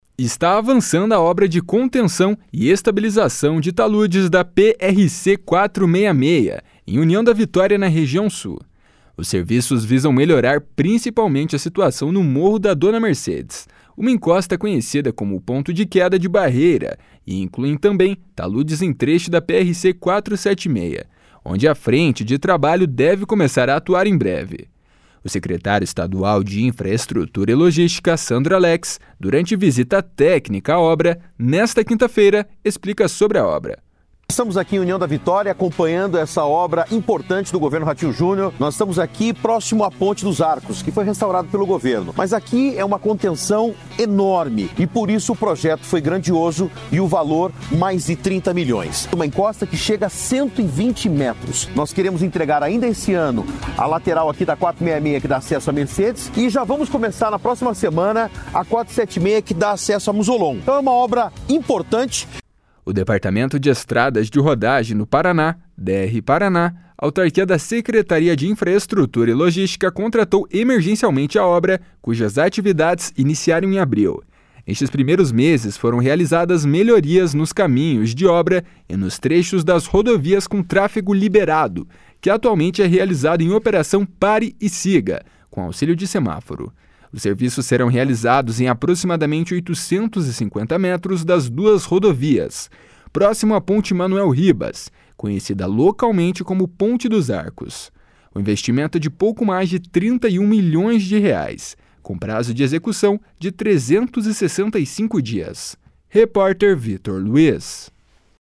O secretário estadual de Infraestrutura e Logística, Sandro Alex, durante visita técnica à obra, nesta quinta-feira, explica sobre a obra.